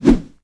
wrenchfire01.wav